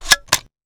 weapon_foley_drop_11.wav